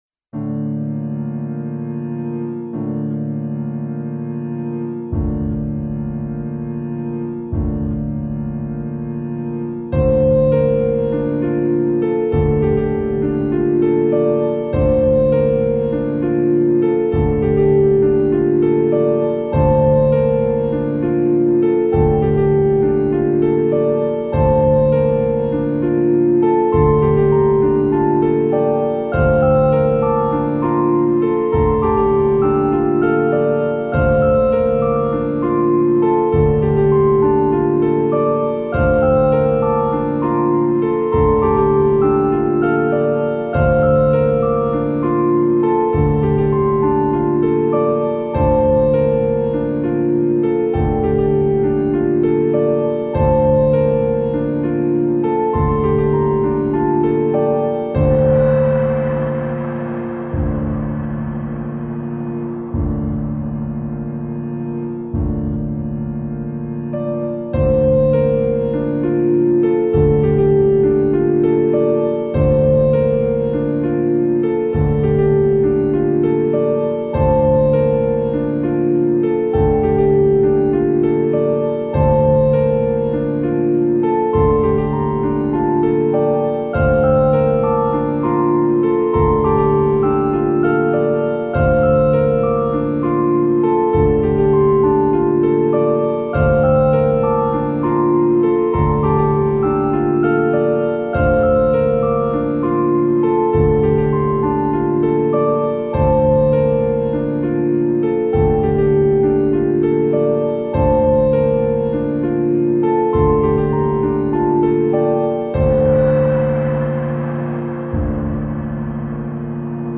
Sad piano.